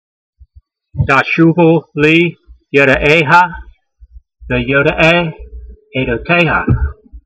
v79_voice.mp3